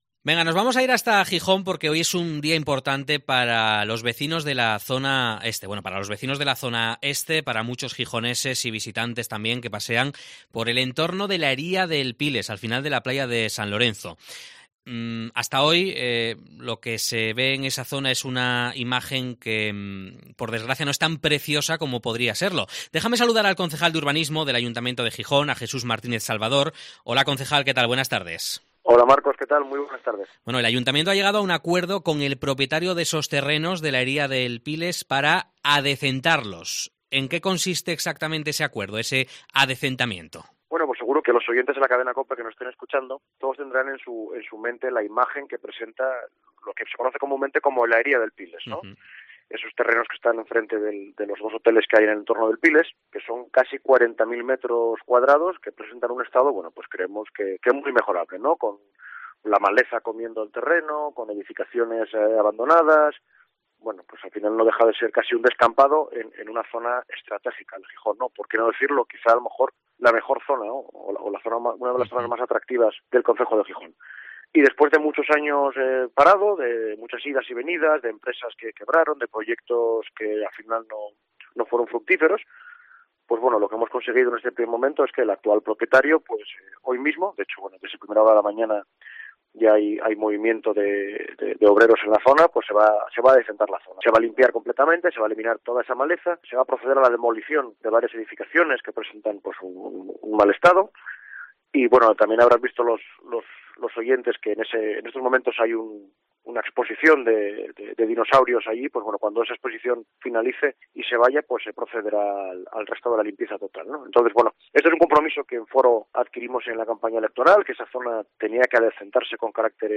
Entrevista al concejal de Urbanismo de Gijón, Jesús Martínez Salvador